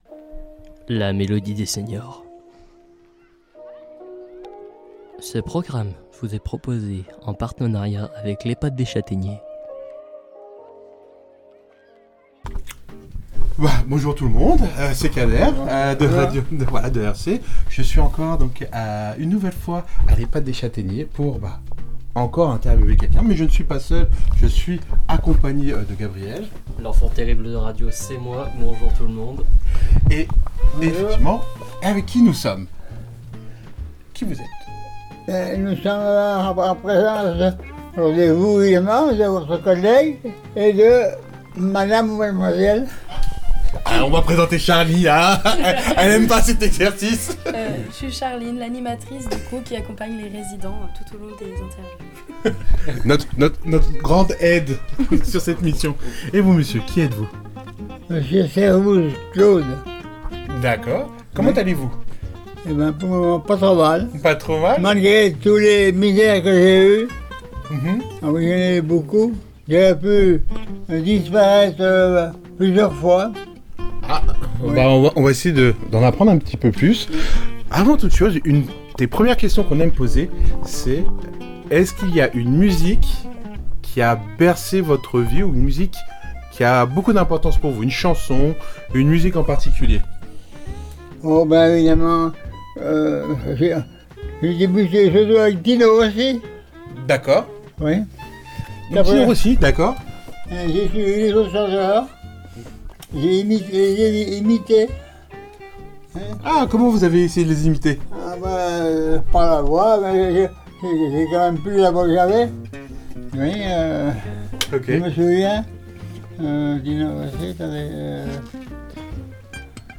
Une rencontre en musique des habitants de l’ehpad des chataigners.